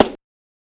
SNARE KNOCK.wav